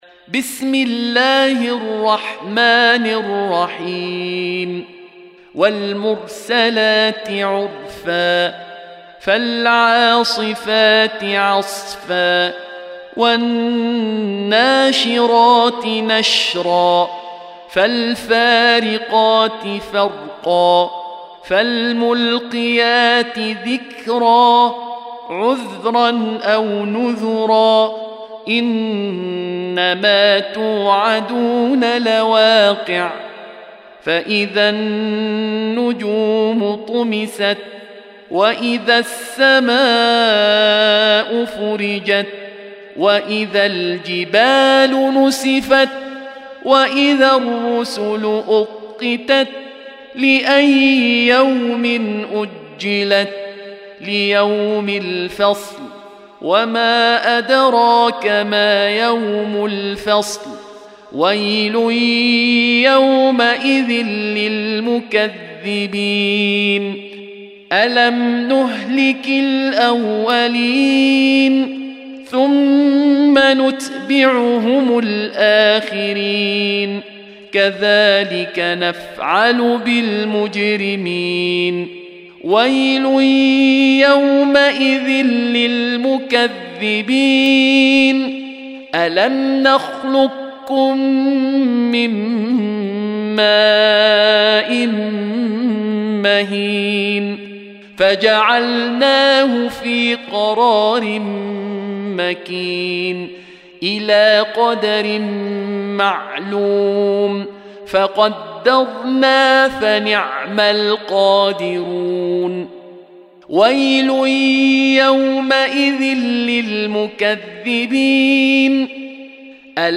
Surah Sequence تتابع السورة Download Surah حمّل السورة Reciting Murattalah Audio for 77. Surah Al-Mursal�t سورة المرسلات N.B *Surah Includes Al-Basmalah Reciters Sequents تتابع التلاوات Reciters Repeats تكرار التلاوات